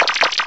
sovereignx/sound/direct_sound_samples/cries/dewpider.aif at master